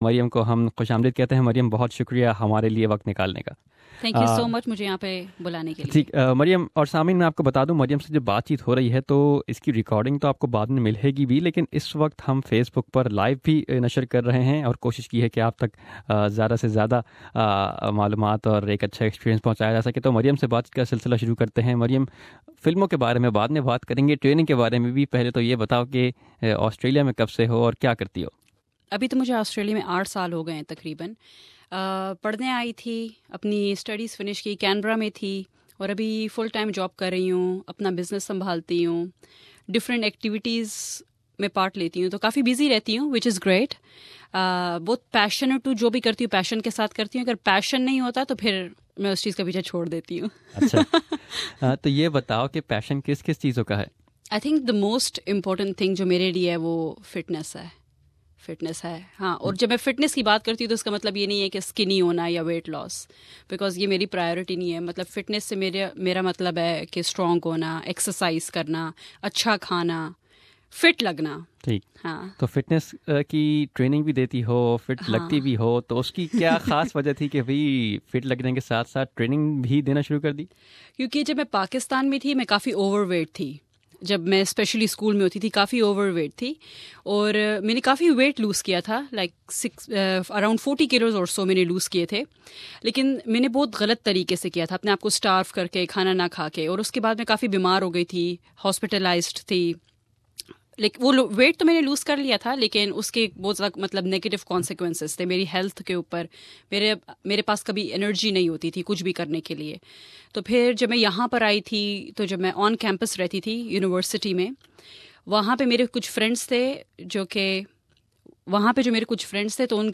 in an interview